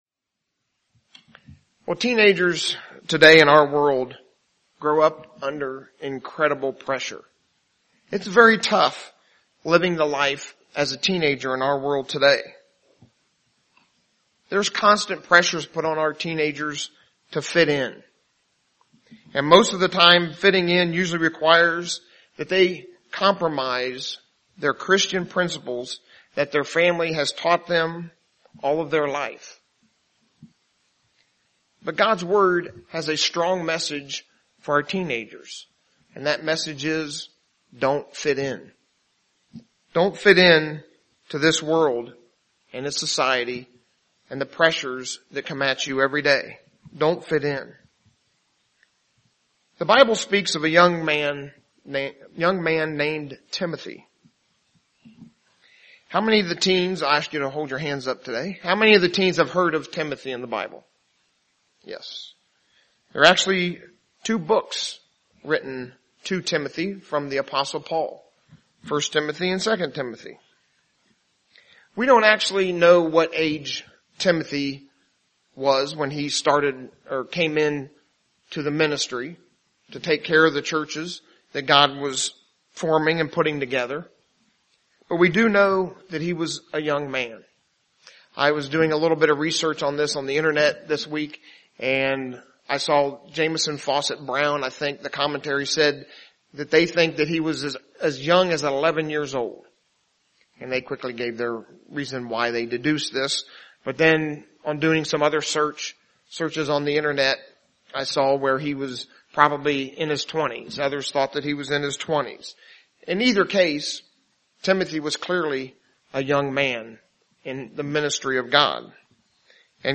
A message directed to the teens and pre-teens using the example of Timothy and the apostle Paul.